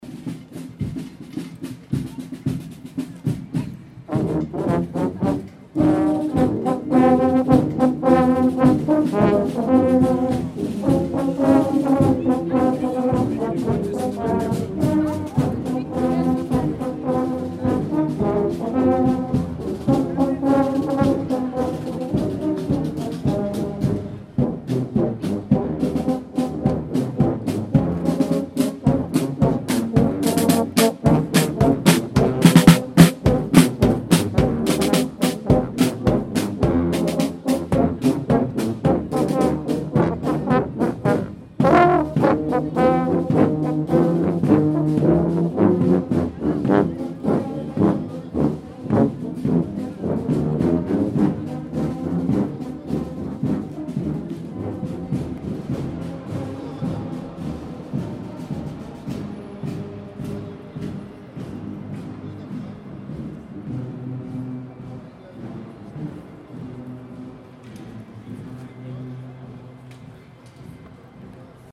Tuba Christmas, Bebbihausen, Basel